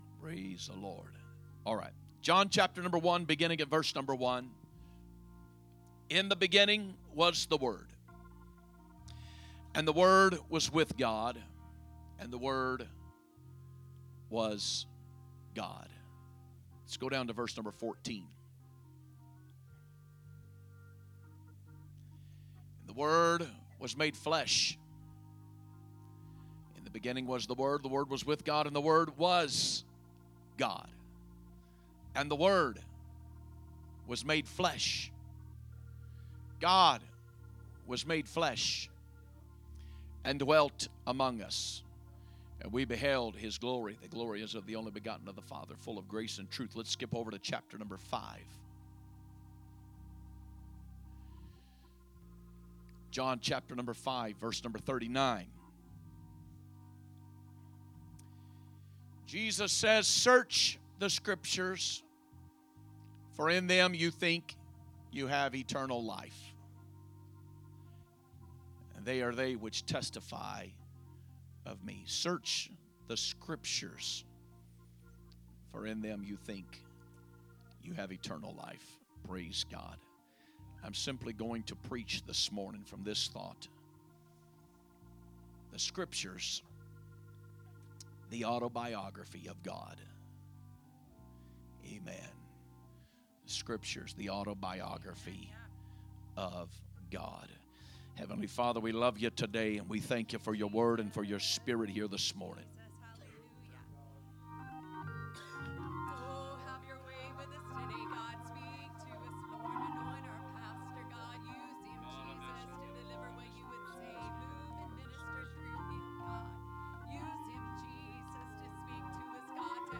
Sunday Morning Message